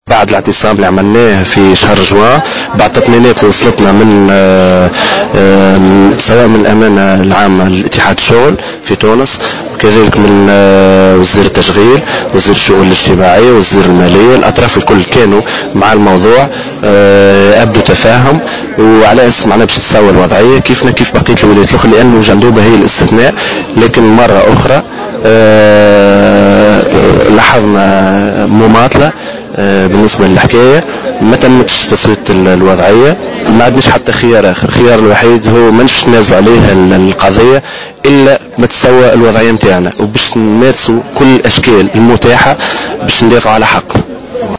L’un des manifestant a affirmé, dans une déclaration accordée à Jawhara FM, que les protestataires réclamaient la régularisation de leur situation en soulignant qu’ils continueront leurs mouvements jusqu’à ce que les autorités de tutelle répondent à leurs revendications.